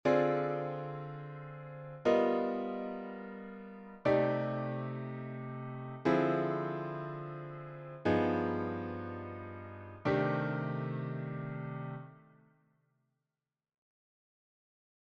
Improvisation Piano Jazz
Accords Composés
Shell Main Gauche + Voicing Main Droite